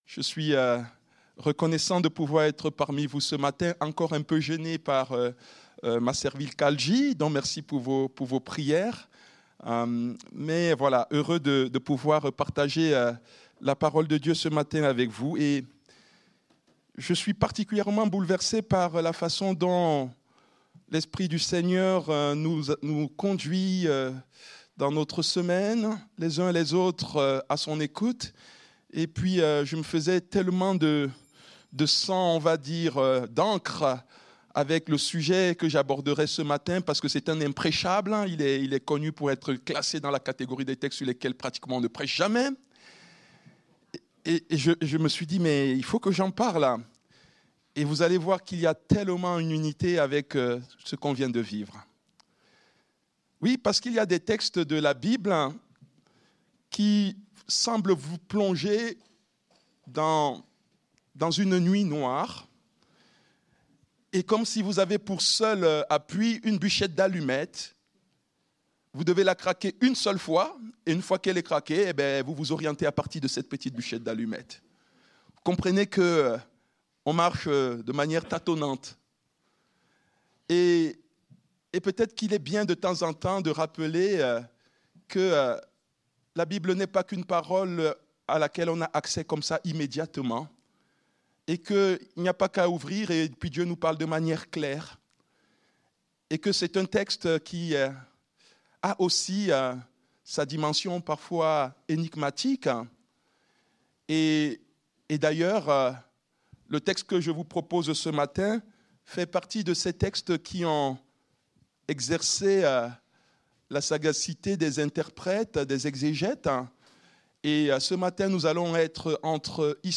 Culte du dimanche 11 Mai 2025, prédication